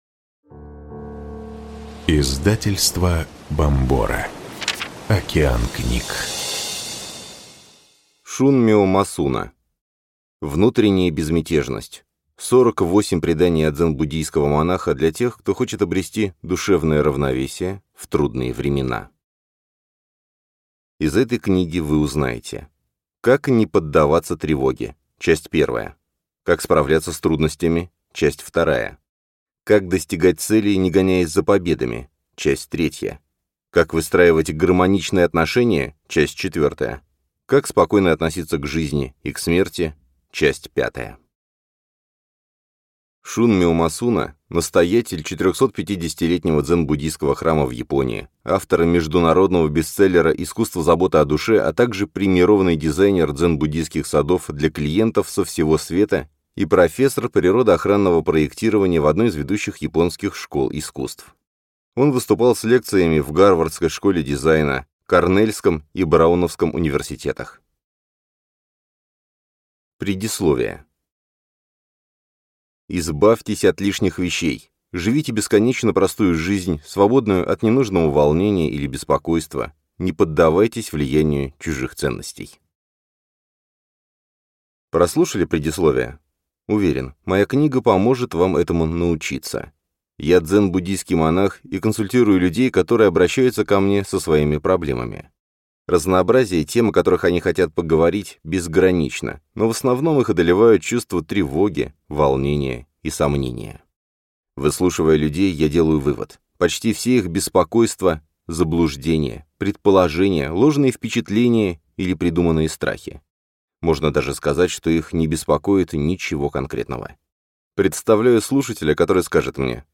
Аудиокнига Внутренняя безмятежность. 48 преданий от дзен-буддийского монаха для тех, кто хочет обрести душевное равновесие в трудные времена | Библиотека аудиокниг